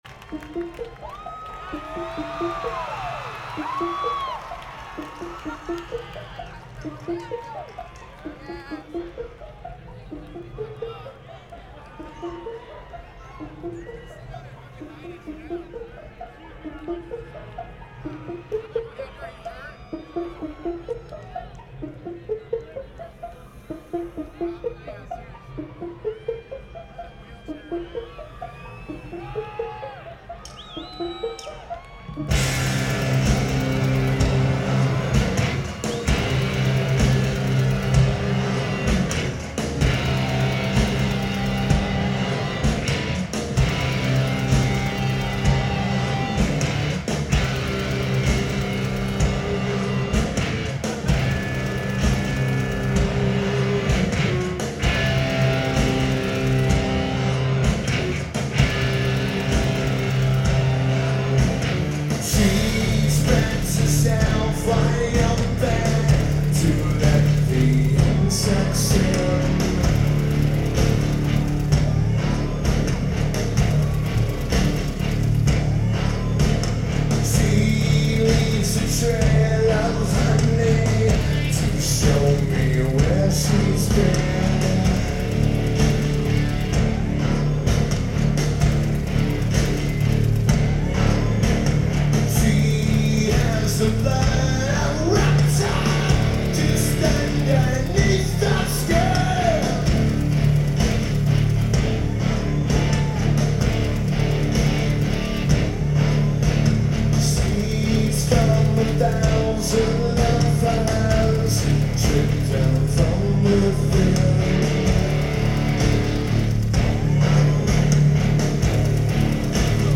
Verizon Wireless Amphitheater
Sounds amazing!